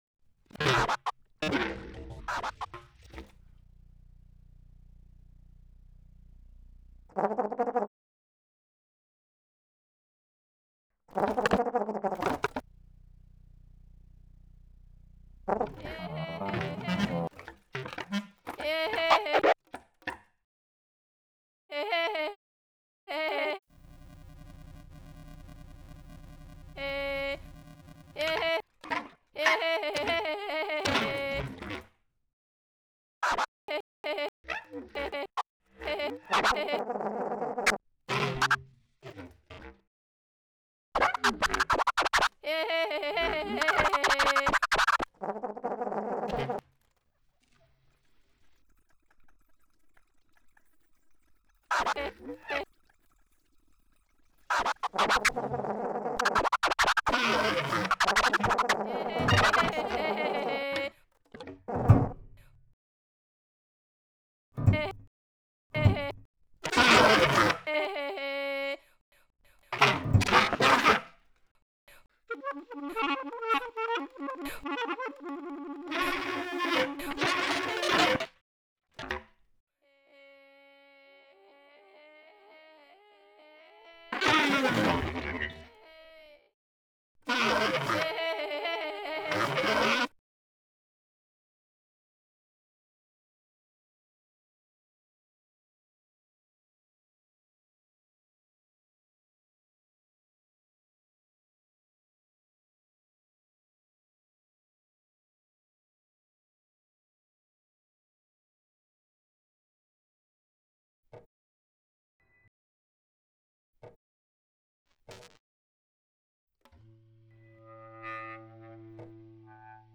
• La « pièce haut-parlante » inscrite sur le support est d’une durée comprise entre 3 min et 3 min 20 s ;
Réalisation à partir d'échantillons sonores réalisés au cours du stage; montage avec Protools